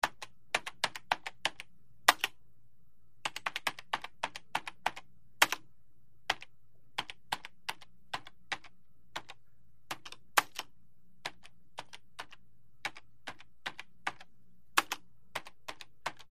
Mac Keyboard 3; Desktop Keyboard; Slow / Sporadic Typing, Close Perspective.